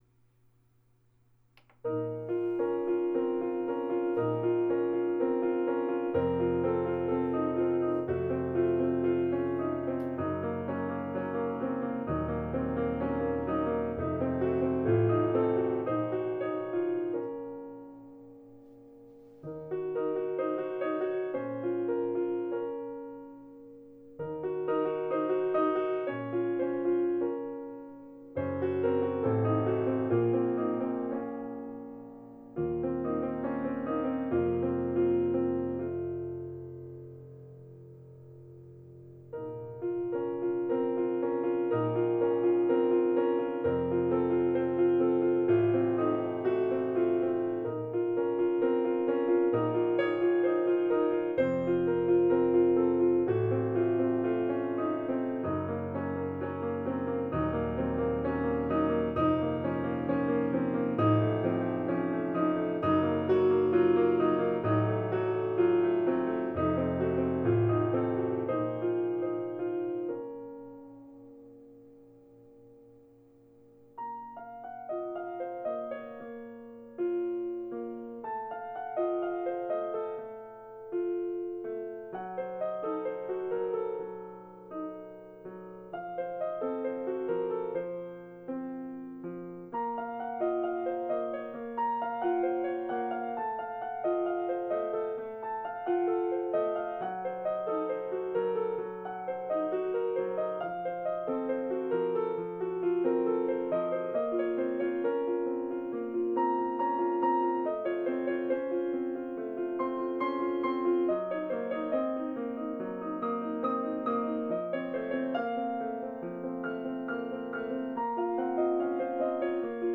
Its sound is much richer and more resonant than my old piano, and there are more choices and features, which will be fun to explore in the coming days.